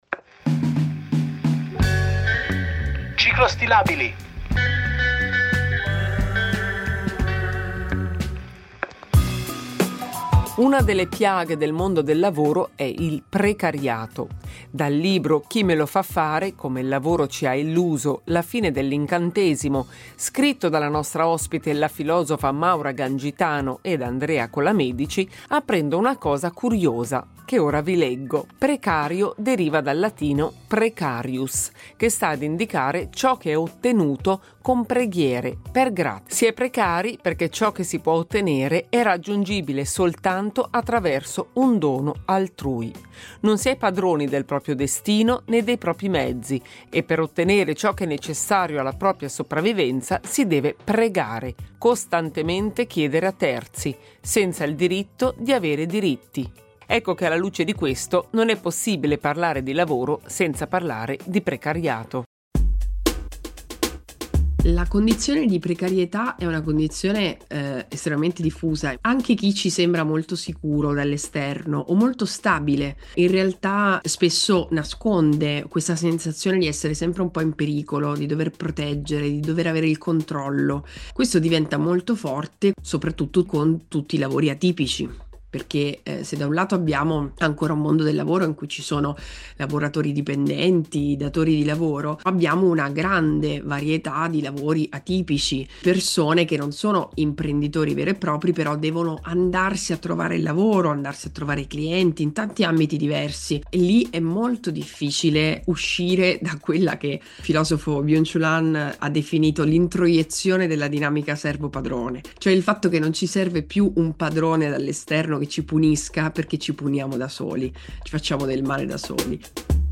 Un dialogo